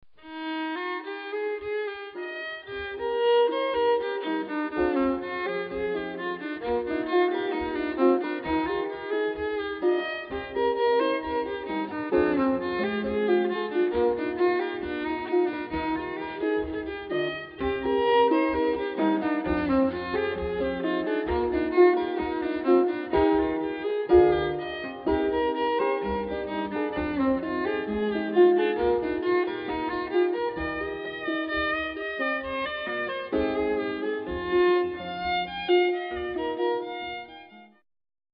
fiddle